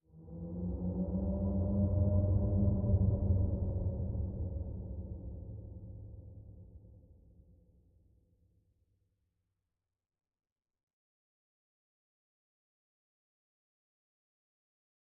Minecraft Version Minecraft Version latest Latest Release | Latest Snapshot latest / assets / minecraft / sounds / ambient / nether / soulsand_valley / voices5.ogg Compare With Compare With Latest Release | Latest Snapshot